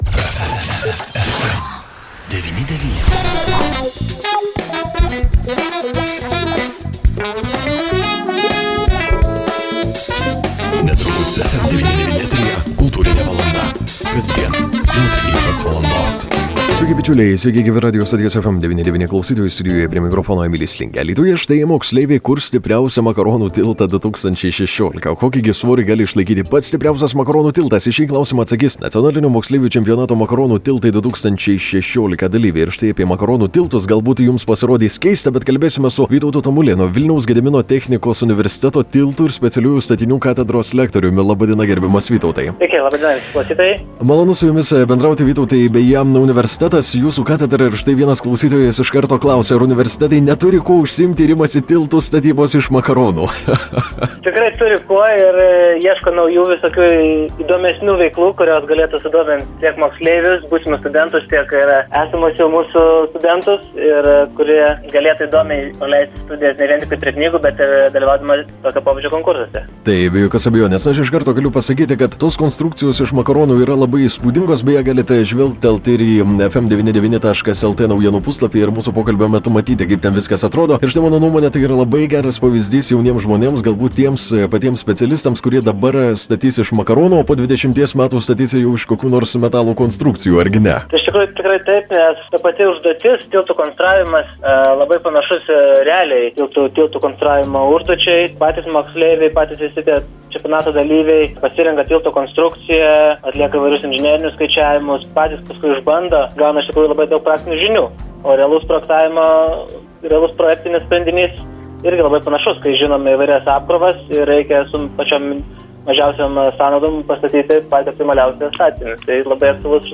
Alytuje moksleiviai kurs stipriausią „Makaronų tiltą 2016“ (interviu)